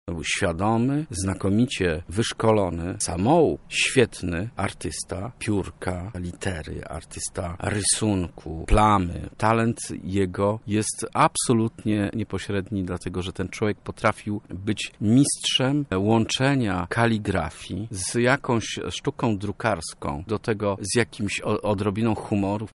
Tak wspomina artystę